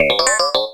combatdrone.wav